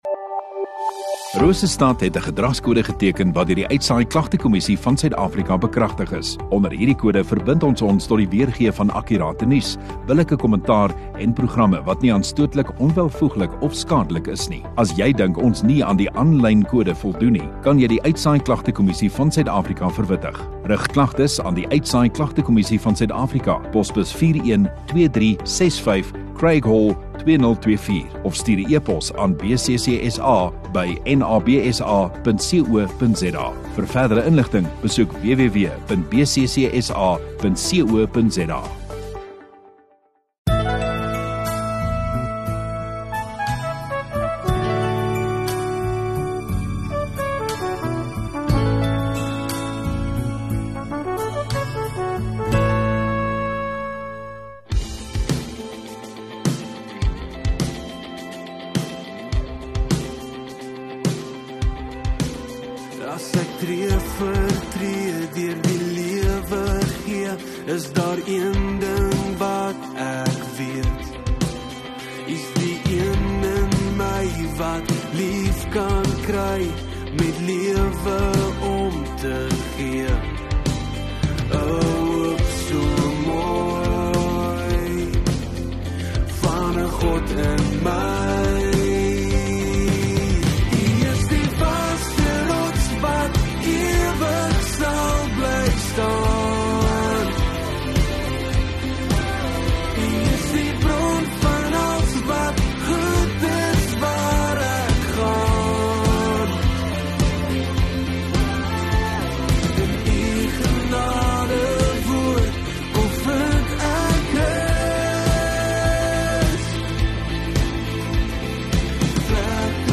2 Nov Saterdag Oggenddiens